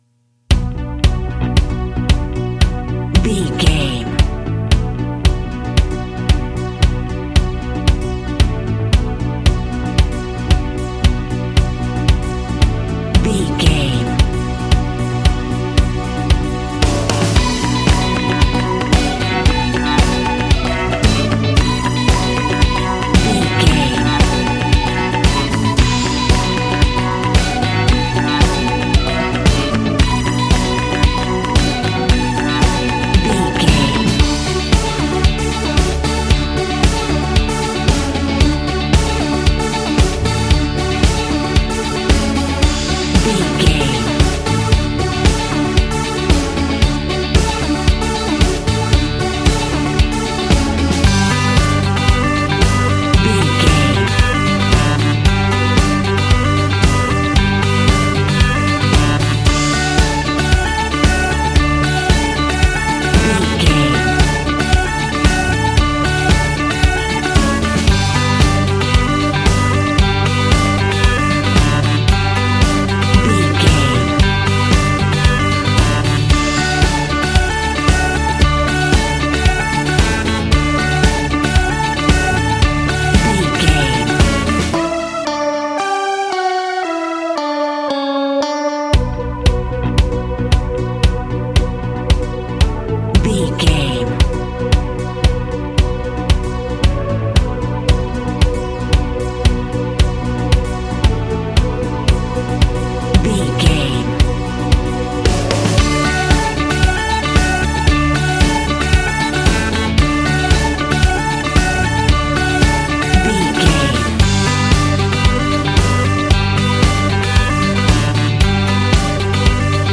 Ionian/Major
D
groovy
powerful
organ
drums
bass guitar
electric guitar
piano